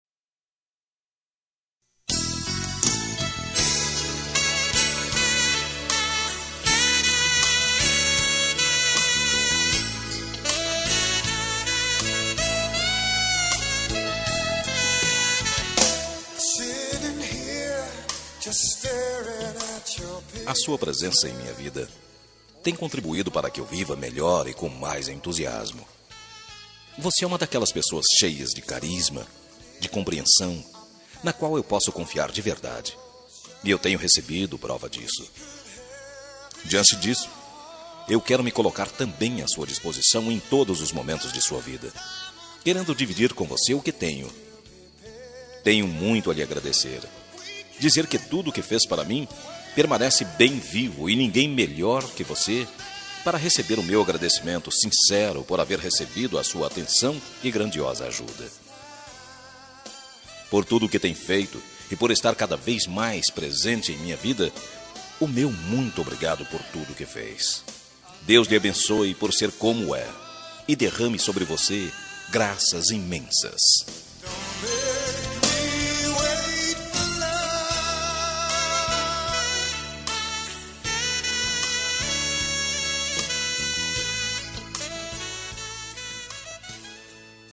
Telemensagem de Agradecimento – Pela Ajuda – Voz Masculina – Cód: 29